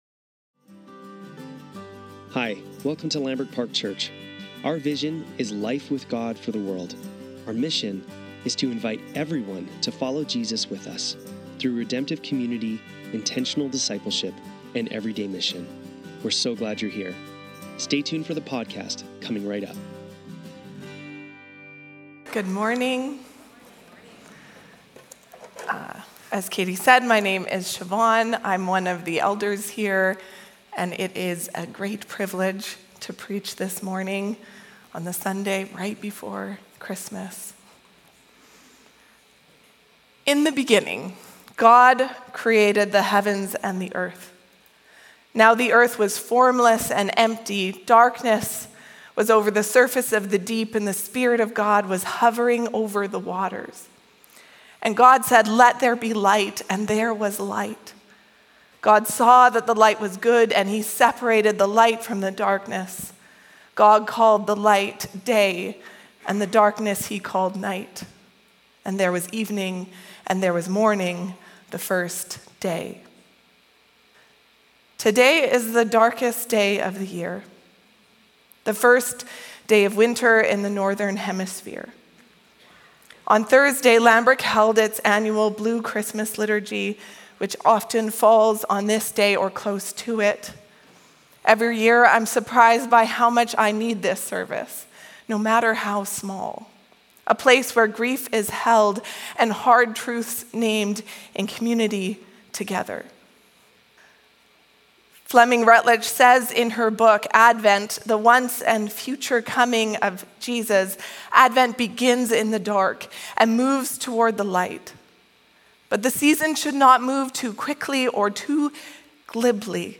Sunday Service - December 21, 2025